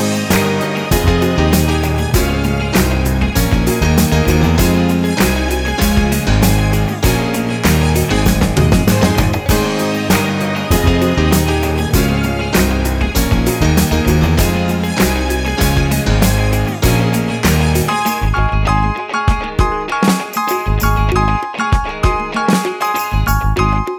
no Backing Vocals Country (Male) 3:02 Buy £1.50